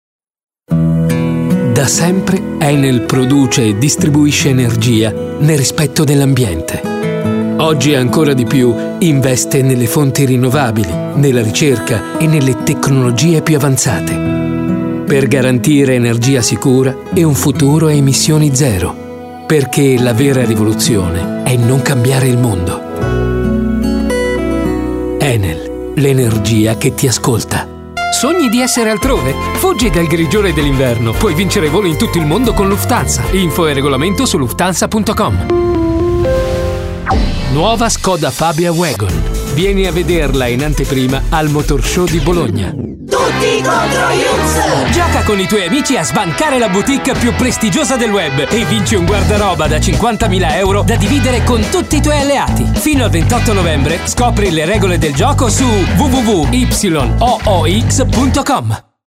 Sprecher italienisch.
Sprechprobe: Werbung (Muttersprache):
Italian voice over artist.